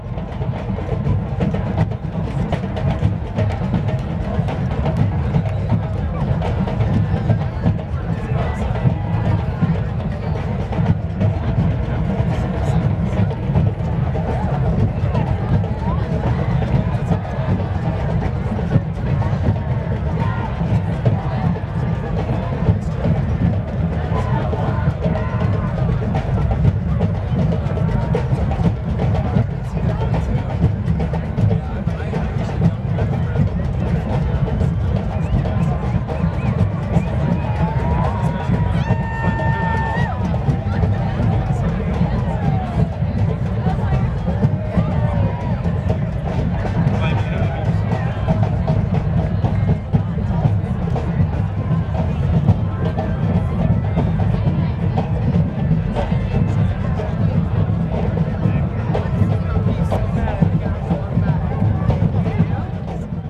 bm_drum_circle_people.R.wav